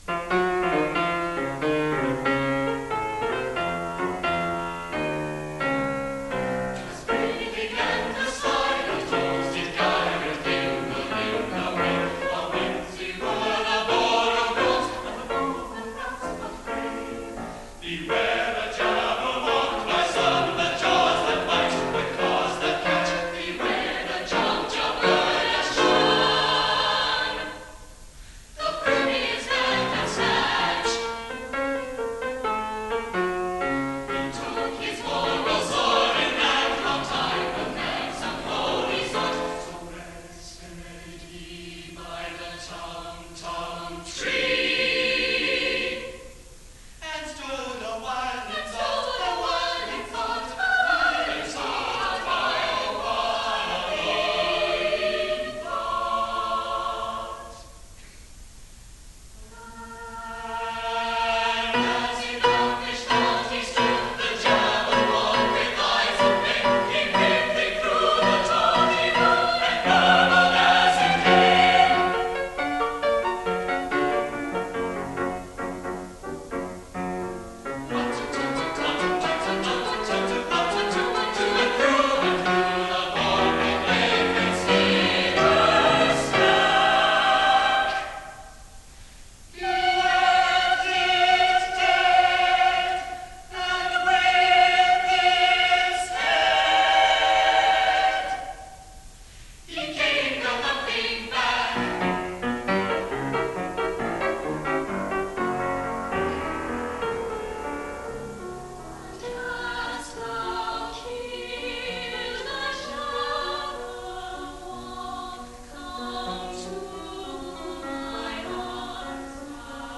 for SATB choir and piano accompaniment!